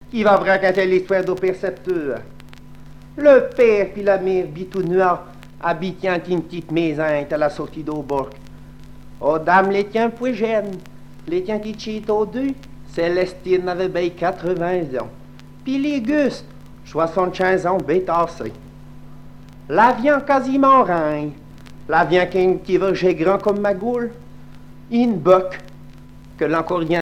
Genre sketch